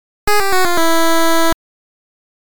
8bit-fail.ogg